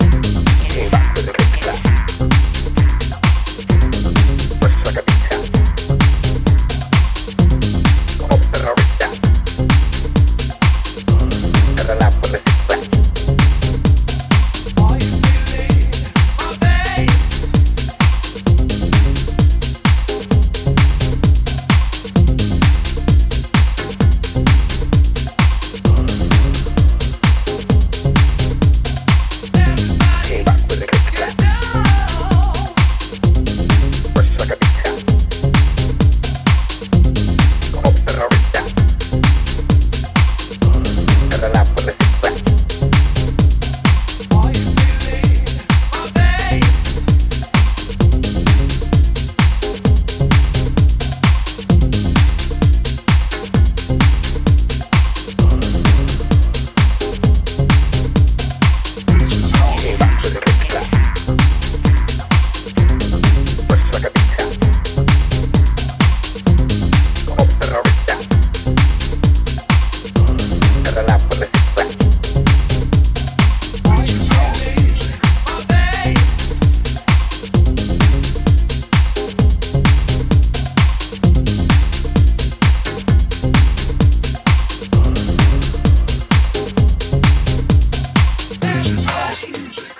クリスピーな裏打ちスネア/ハットが推進力を強めたアブストラクト・ミニマル
陽性なパーティーフレイヴァがこれからの季節にもぴったりな秀逸ハウスを展開